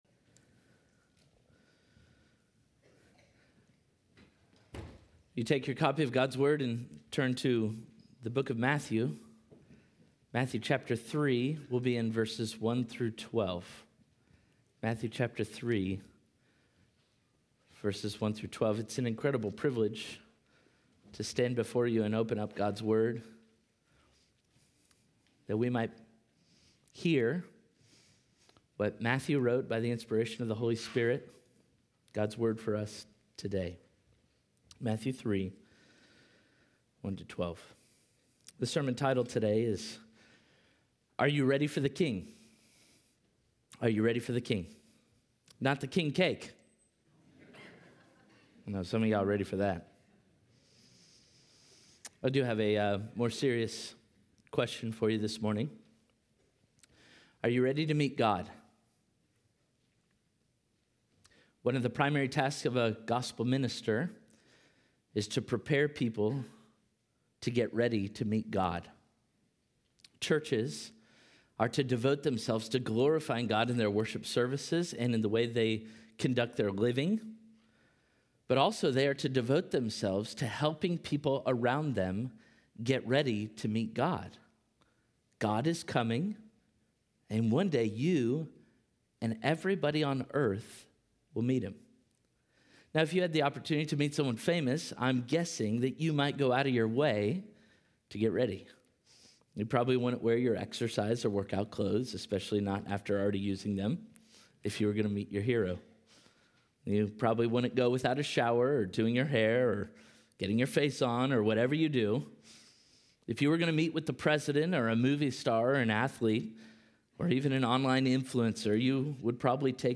Parkway Sermons Are You Ready for the King?